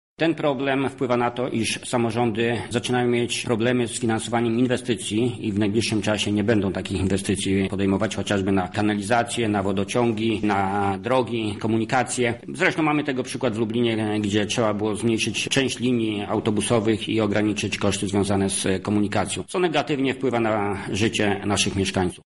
Chcemy porozmawiać o rozwiązaniach na przyszłość – mówi senator Jacek Bury: